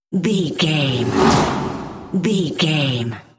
Whoosh airy creature
Sound Effects
Atonal
ominous
haunting
eerie